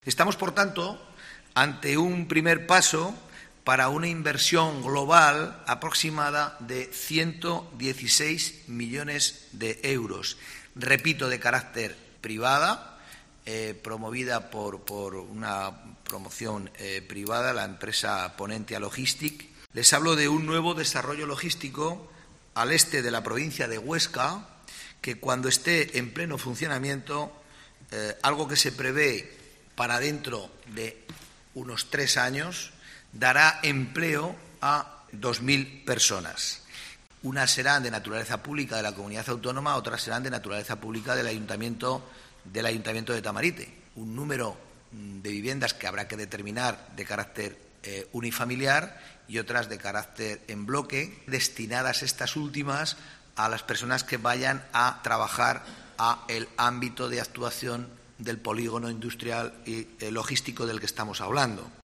El consejero Octavio López, anuncia los detalles del proyecto logístico-residencial de Tamarite